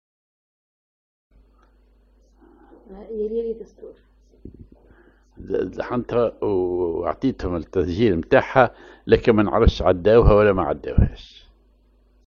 Maqam ar عرضاوي
Rhythm ar غيطة
genre أغنية